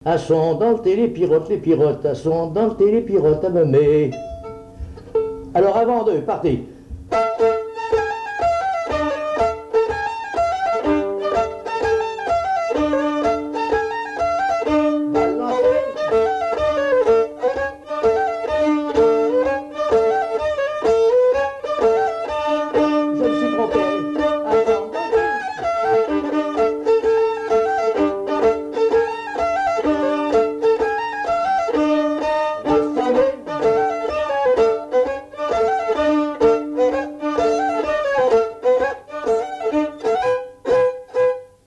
Mémoires et Patrimoines vivants - RaddO est une base de données d'archives iconographiques et sonores.
Divertissements d'adultes - Couplets à danser
danse : branle : avant-deux
Pièce musicale inédite